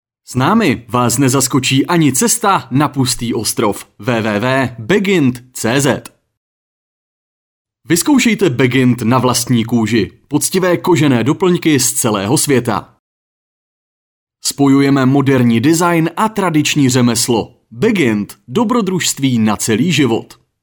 Profesionální mužský hlas (voiceover, reklama, dokument)
Dokážu se přizpůsobit a díky zkušenostem v nahrávání různých typů audia od reklamních spotů, přes dokumenty až po pozvánky na akce dokážu vystihnout kýženou náladu nahrávky.